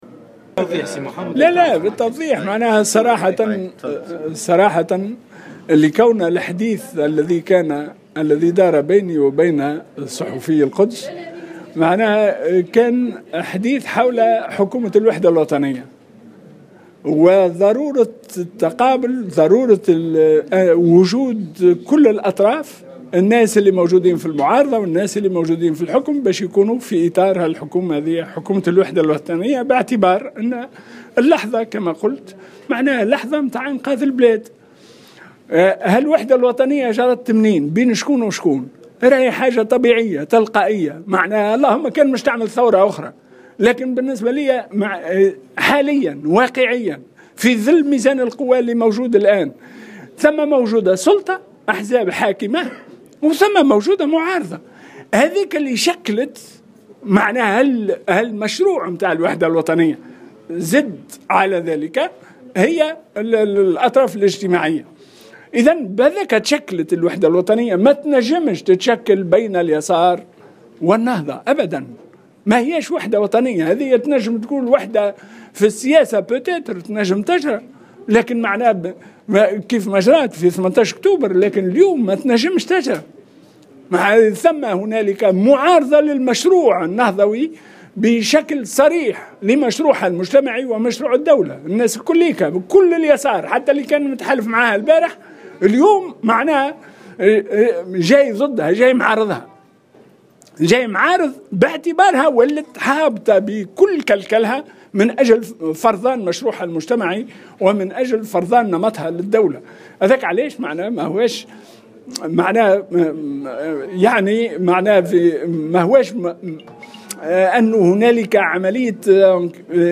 أكد الأمين العام للحزب الإشتراكي محمد الكيلاني في تصريح لمراسل الجوهرة "اف ام" على هامش المؤتمر التوحيدي لليسار الذي عقد اليوم الخميس أن تصريحاته لصحيفة القدس العربي أخرجت من سياقها مؤكدا أنه كان يتحدث عن حكومة الوحدة الوطنية التي جمعت بين أطراف مختلفة بهدف انقاذ البلاد على حد قوله.